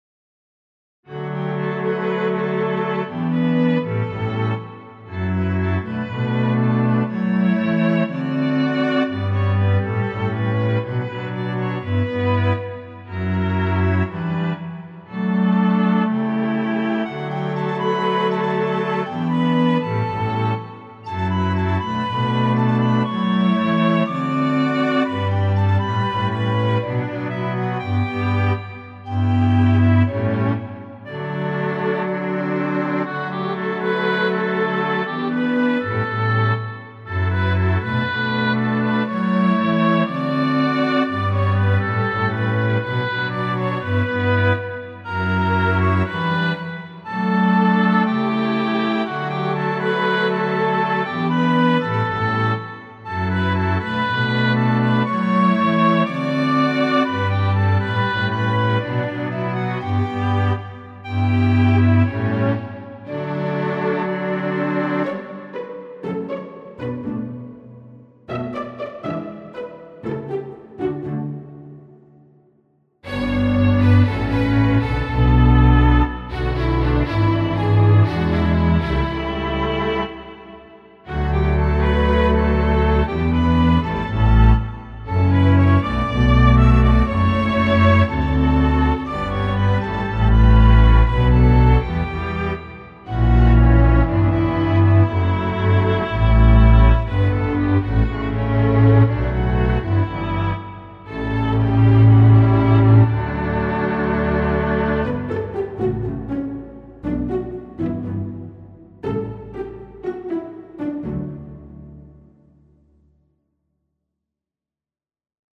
Rechtzeitig zum Neujahrskonzert meldet sich das Niarts Symphonieorchester zurück mit drei kleinen musikalischen Stücklein für Streichorchester und einem mittellangen Klavierstück.
Allerdings wurden auch hier die Stücke von Hand umgearbeitet und schließlich unter Zuhilfenahme von Vital Instruments neu gesampelt und in Magix Music Maker MX Pro remixed: